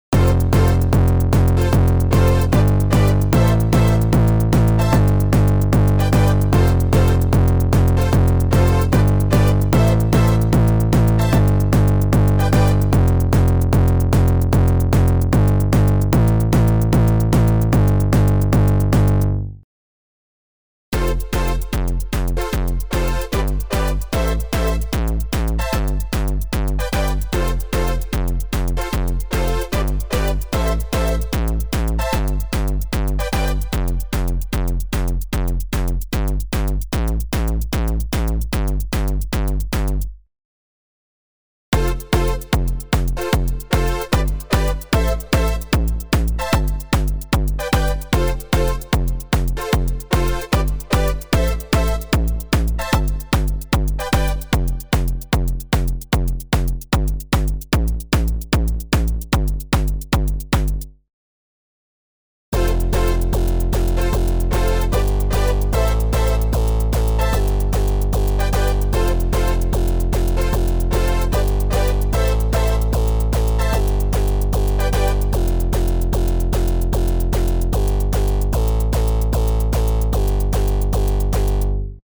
For example, a visit to the Rob Papen HQ by Headhunterz (a.k.a. Dutch hardstyle DJ/producer Willem Rebergen) — recently ranked 11th in DJ Magazine’s annual Top 100 DJs poll — resulted in the aforesaid dedicated HardStyle BD Basses sound bank and also a new distortion effect within Predator 1.6.4 — proof positive of the soft synth’s versatility.
Predator1.6.4_HardStyle_BD_Basses.mp3